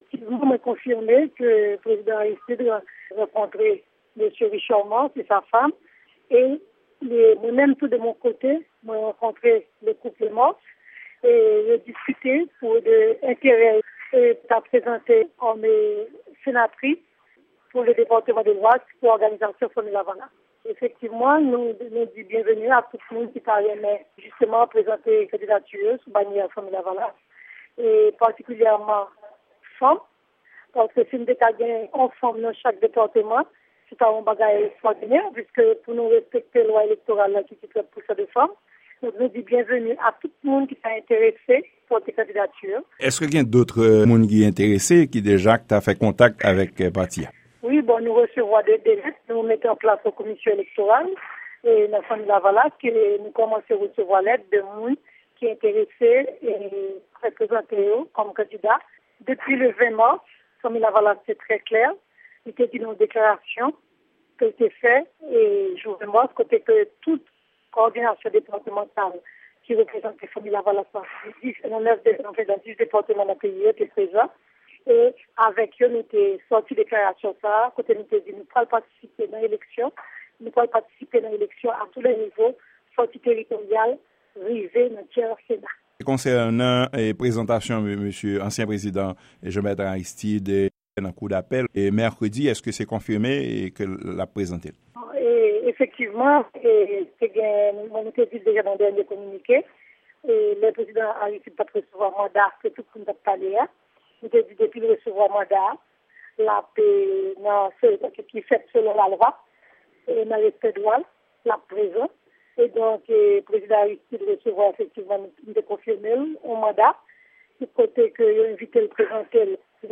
Deklarasyon Dr. Maryse Narcisse nan mikwo Lavwad Lamerik